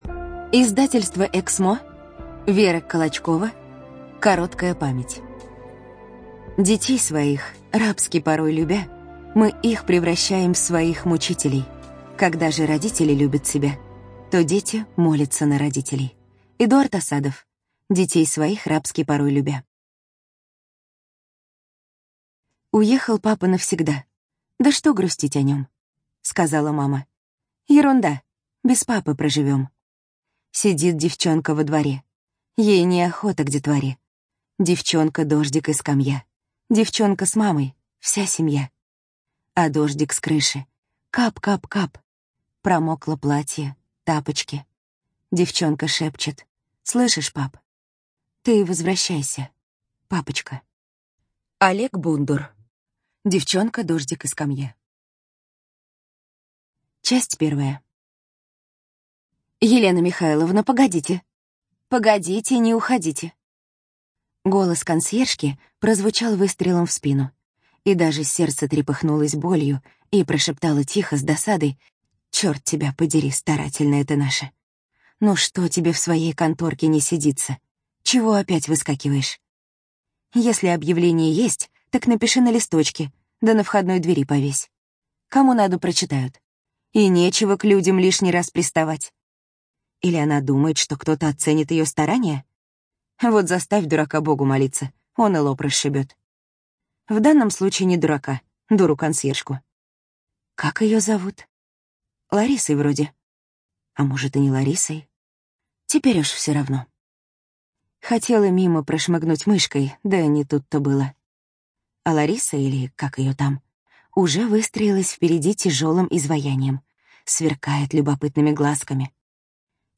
ЖанрСовременная проза
Студия звукозаписиЭКСМО